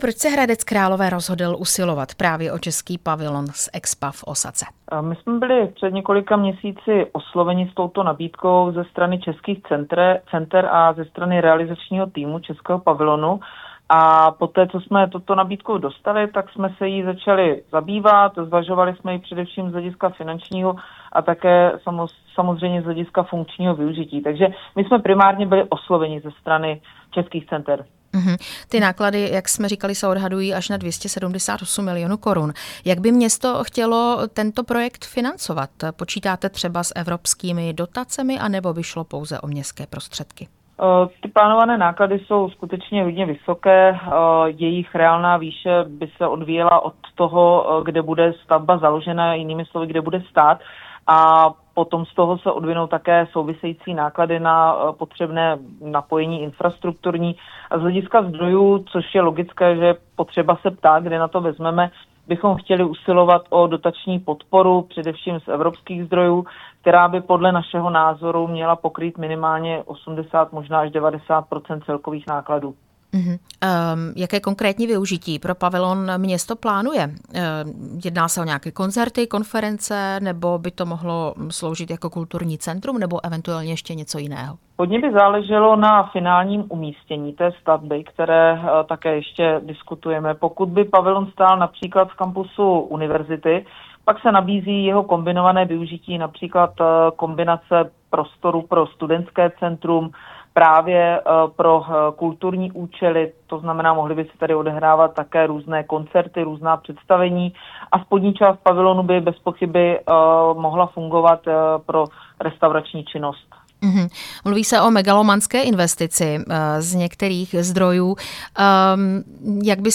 Rozhovor s primátorkou Hradce Králové Pavlínou Springerovou